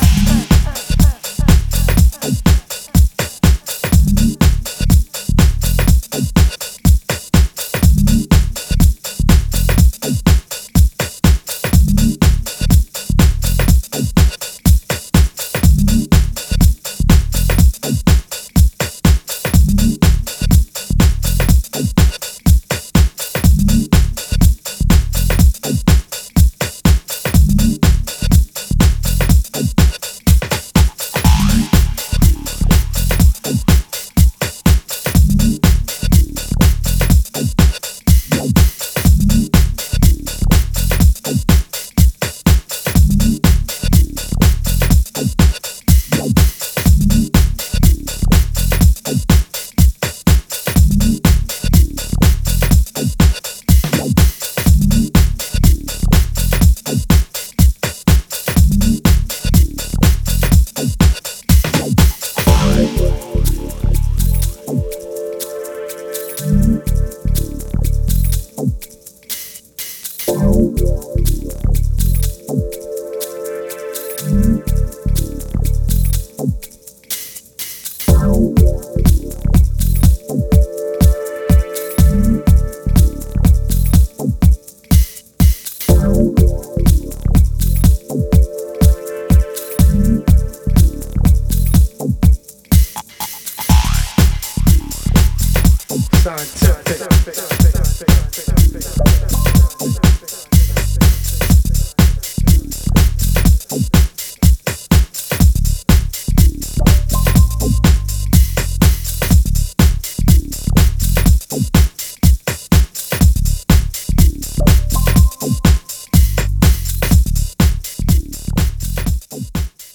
is a deep late night groover
set with bonus beats to boot.